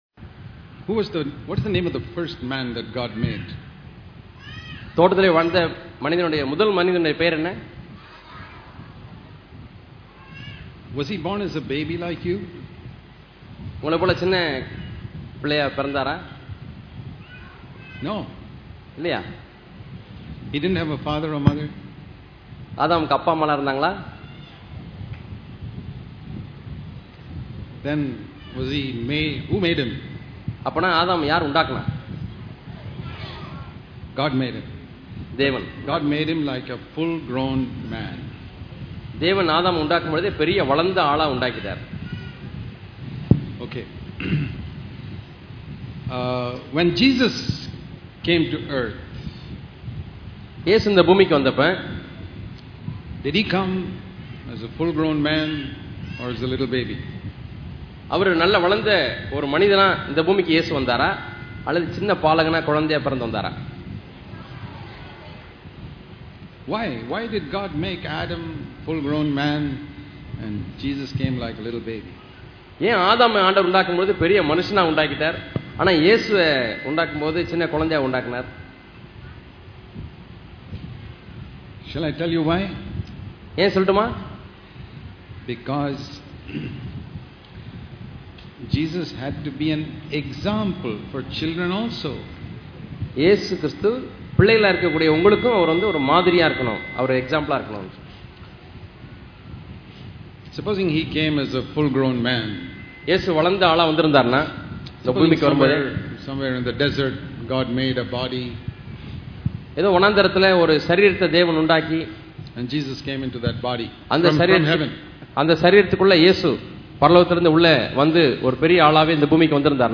Following Jesus Example Of Doing Good (Children) Holiness and Fellowship Click here to View All Sermons இத்தொடரின் செய்திகள் தேவன் எல்லாவற்றையும் நன்றாக அறிந்துள்ளார் என்ற உணர்வை பெற்றுள்ளேனா?